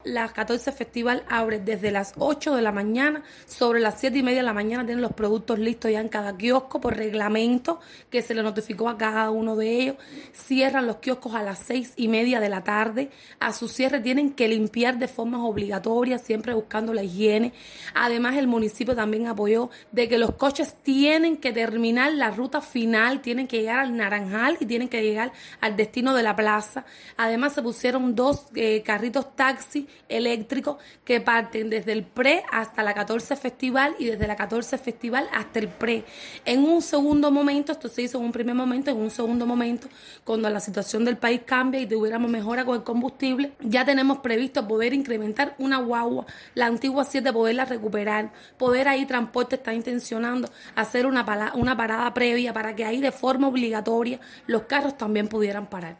Las opiniones de la población han sido tomadas en cuenta por parte de las autoridades del municipio. Al respecto, informa la viceintendente: